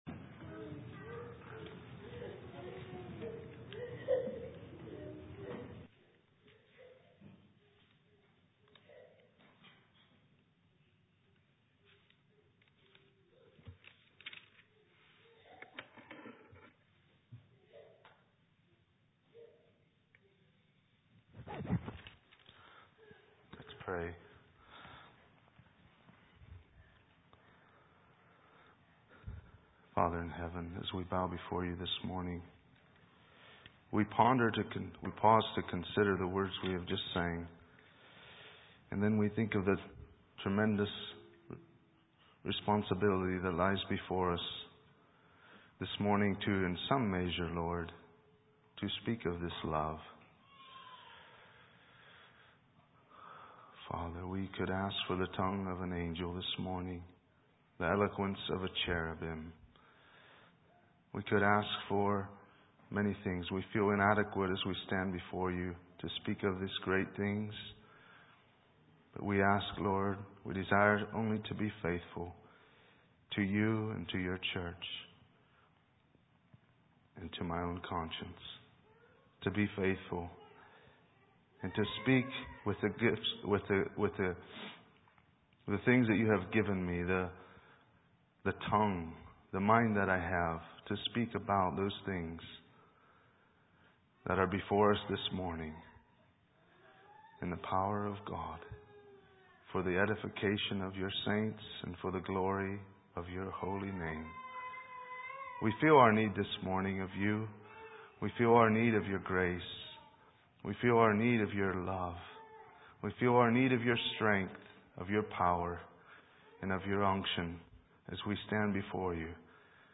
Sermon set